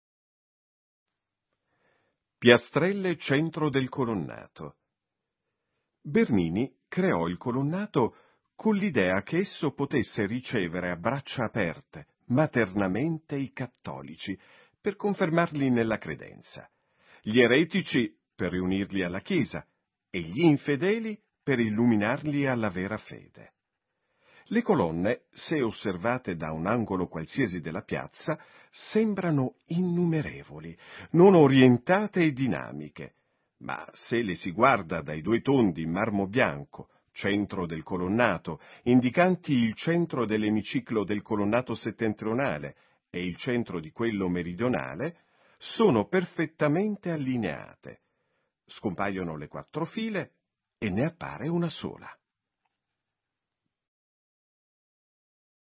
Testo del frate
frate-it-piastrella-centro-colonnato.mp3